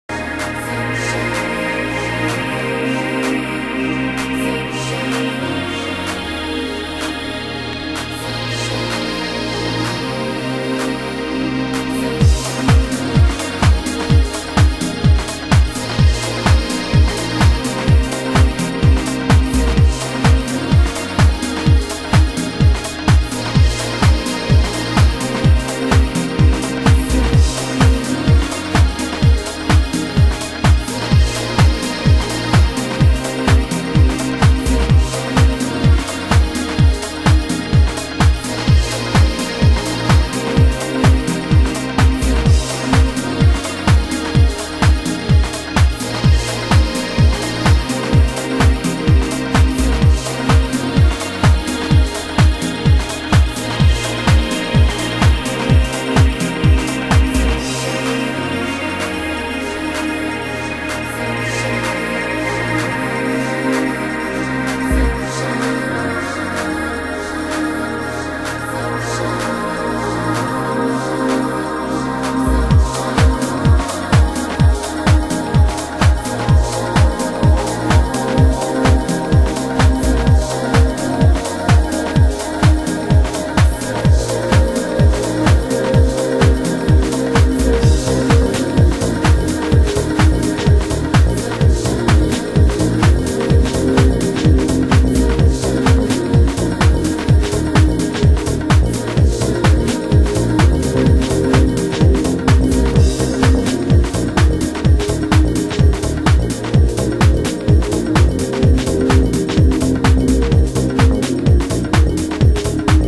盤質：A1に小さなプレスノイズ （不純物）/概ね良好　　盤質：概ね良好